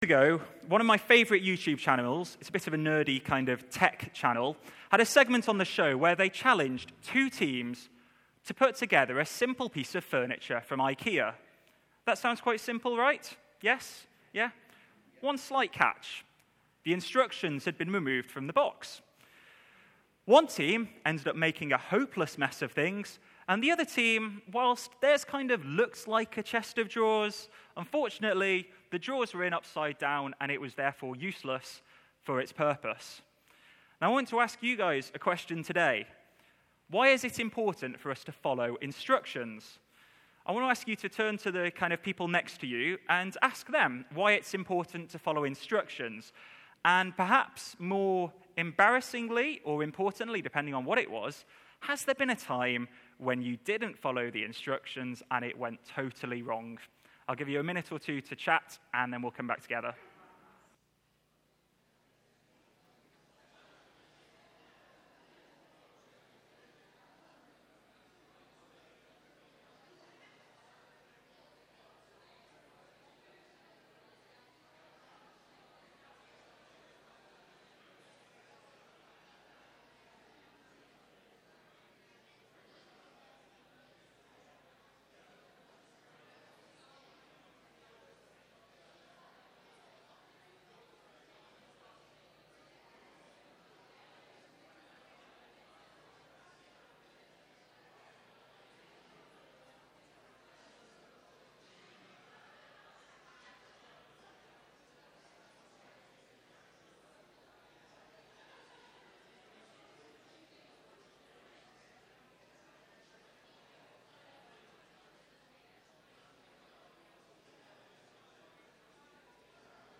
A follow up sermon to the previous week's baptisms. Please note there is a long pause between 0:30 and 3:00 where discussion took place between the congregation.